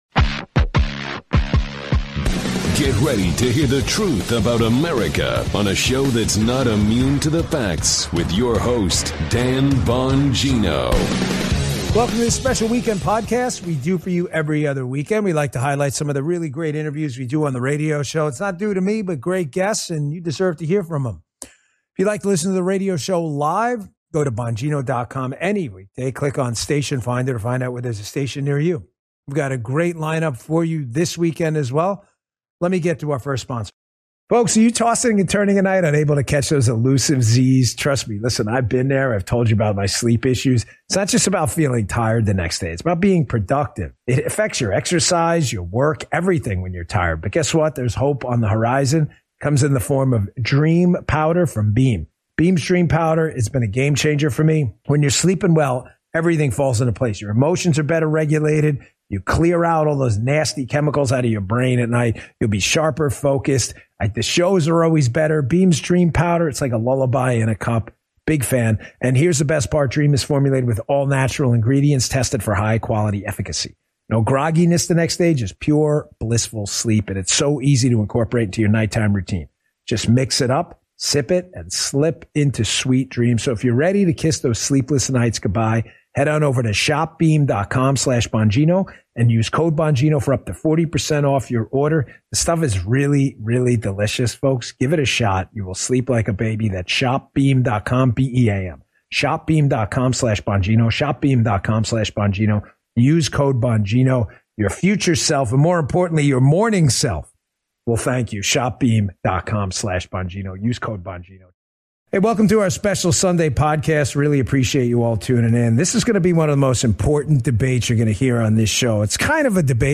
SPREAD THE WORD MP3 Audio Summary ➡ This text is about a podcast hosted by Dan Bongino, where he discusses various topics and interviews guests. In this episode, he talks about a sleep aid product and then interviews Speaker Mike Johnson about a controversial bill called the FISA bill. Bongino believes this bill allows for spying on Americans, but Johnson defends it, saying it’s necessary for national security and has been reformed to prevent abuse.